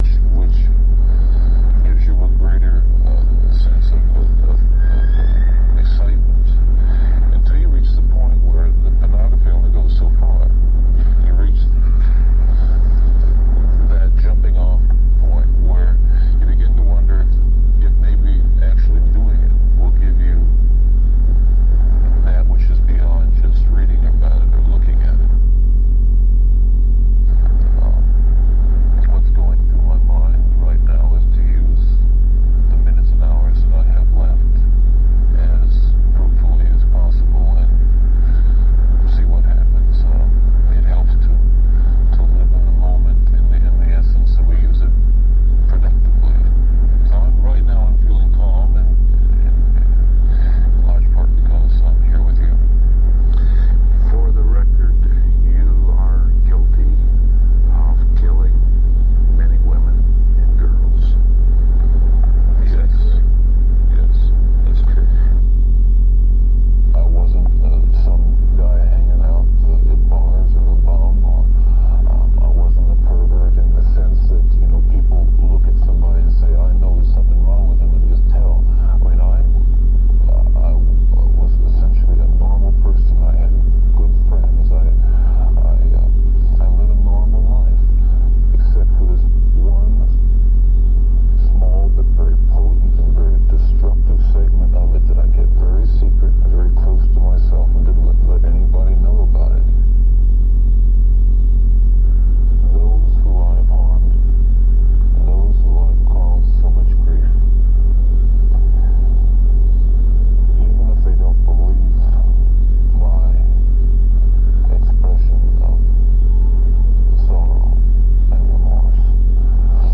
throbbing and rough synth
• Genre: Death Industrial / Power Electronics